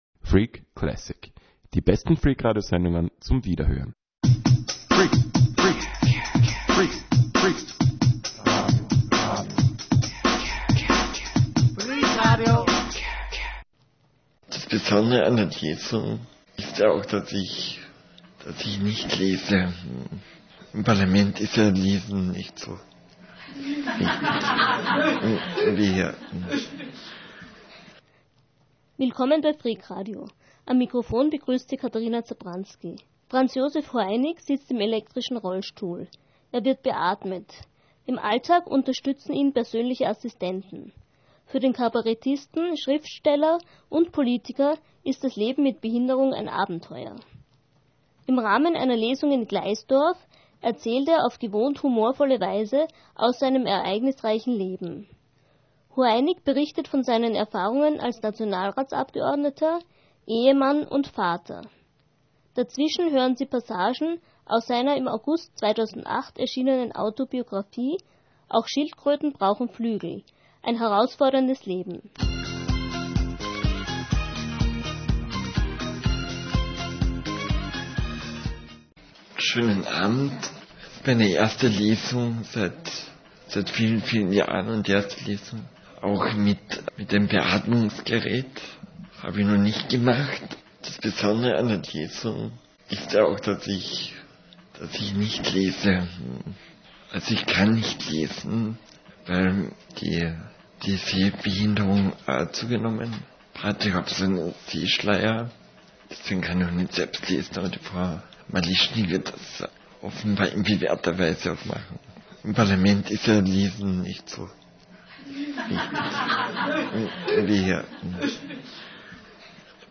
Wir erfahren, warum ihm seine Eltern an Stelle des versprochenen Hundes eine Schilkröte schenkten. Das Material zur Sendung stammt von einer Lesung, die 2009 in Gleisdorf (Steiermark) stattgefunden hat.